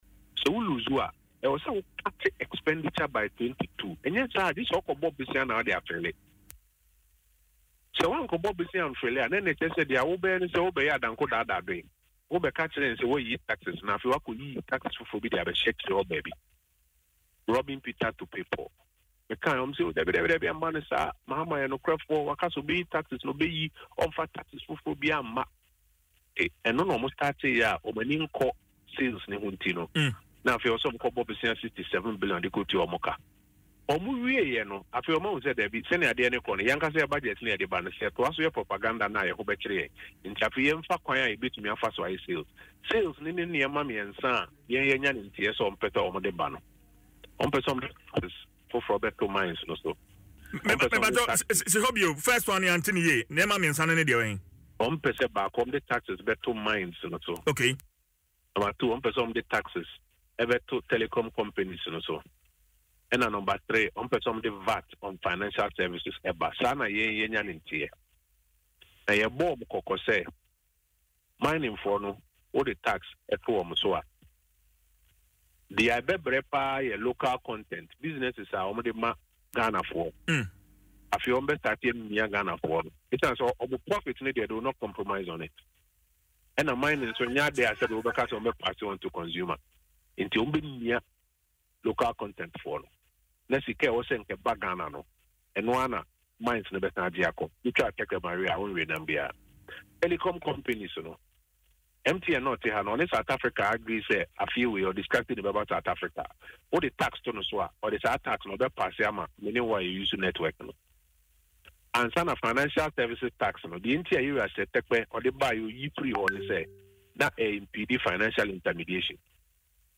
The former Minister for Works and Housing disclosed this in an interview on Adom FM’s Dwaso Nsem, stating that such a move would be a betrayal of Ghanaians’ trust.